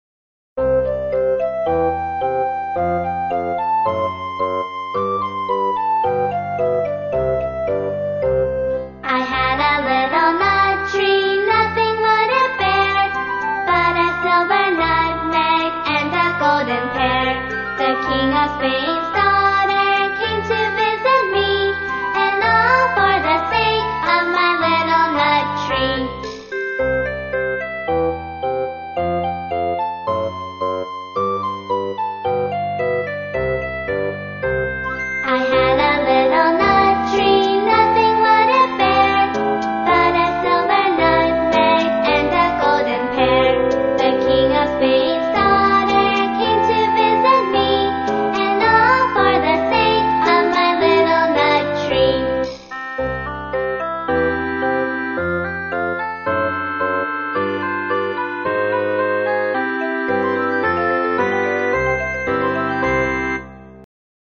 在线英语听力室英语儿歌274首 第80期:I had a little nut tree的听力文件下载,收录了274首发音地道纯正，音乐节奏活泼动人的英文儿歌，从小培养对英语的爱好，为以后萌娃学习更多的英语知识，打下坚实的基础。